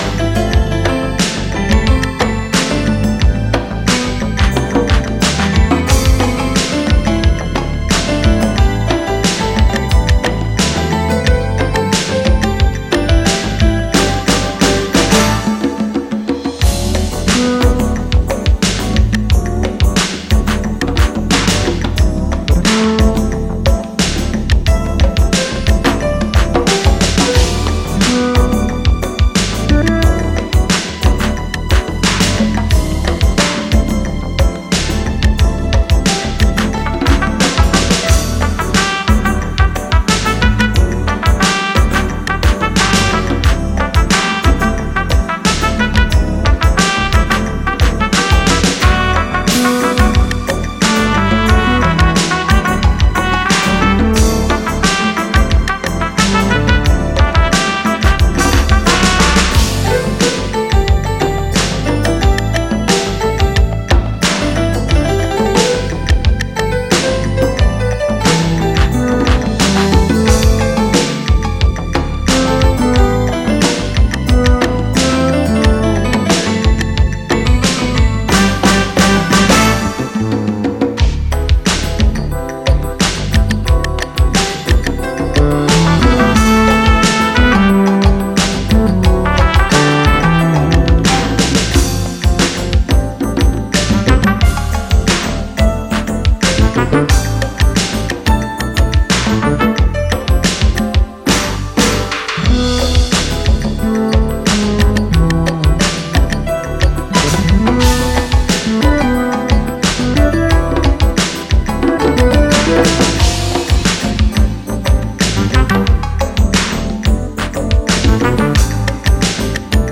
meltingly sweet 80s urban funk